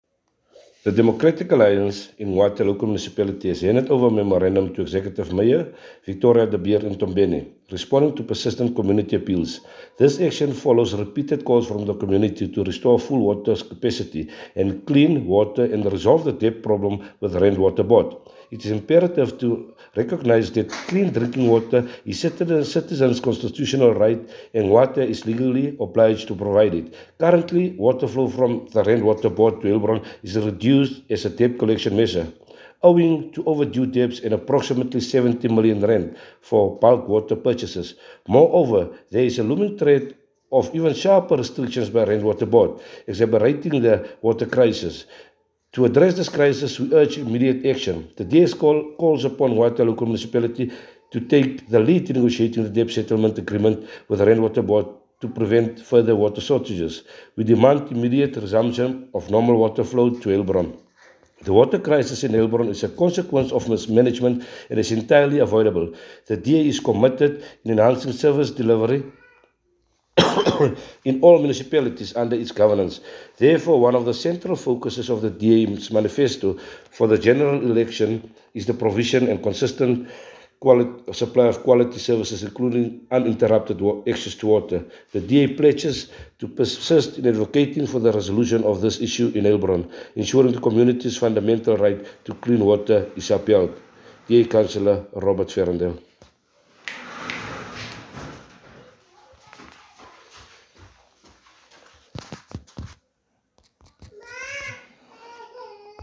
Afrikaans soundbites by Cllr Robert Ferendale.